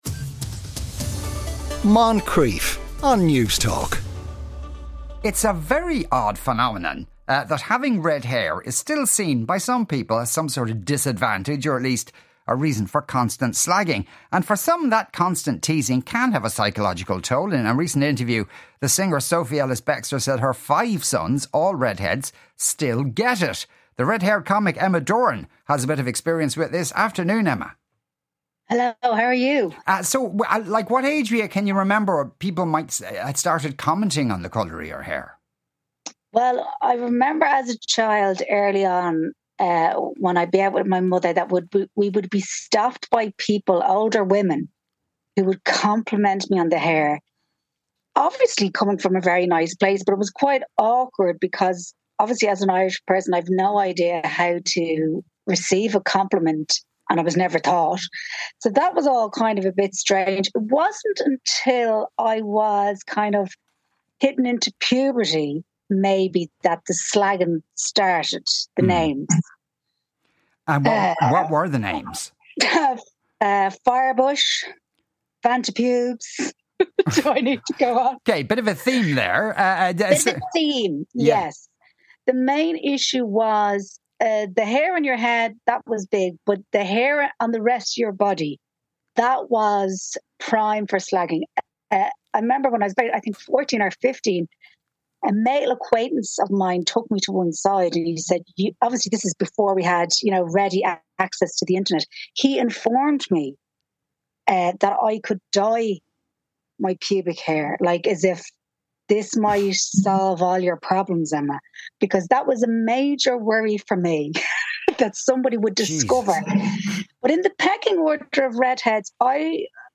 Comedian and Writer